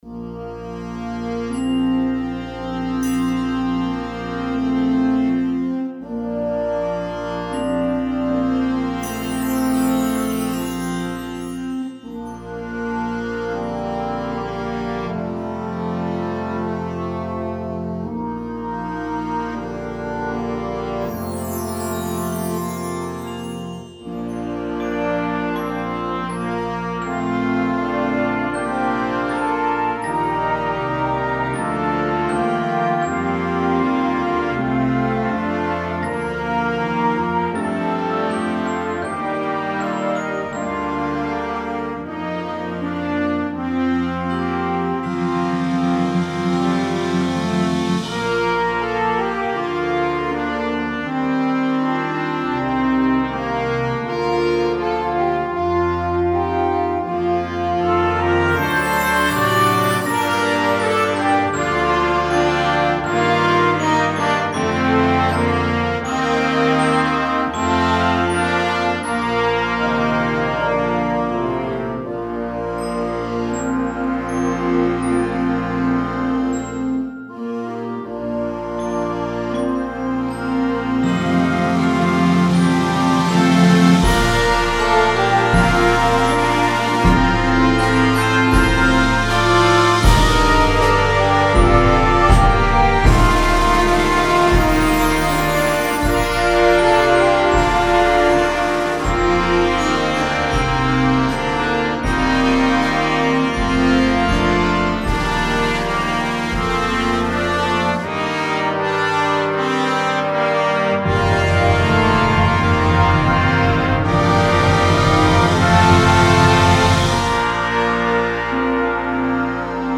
is a lyrical piece for beginning Concert Band